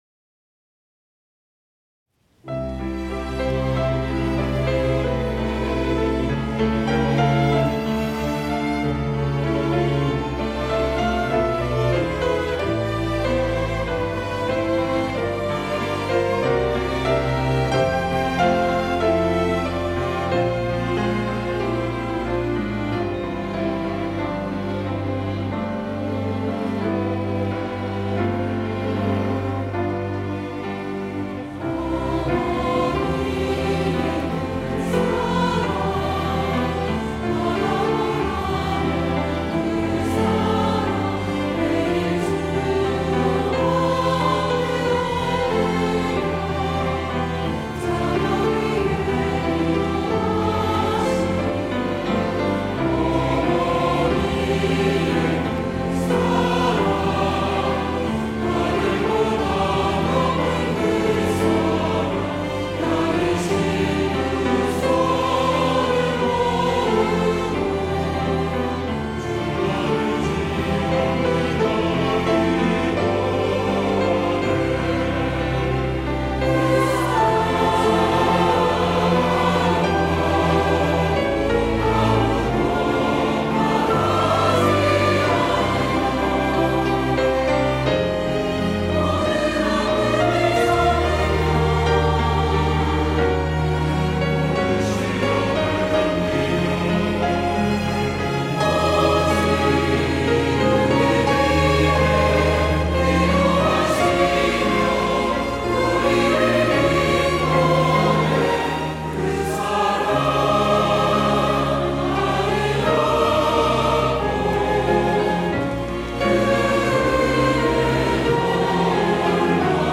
호산나(주일3부) - 어머니의 기도
찬양대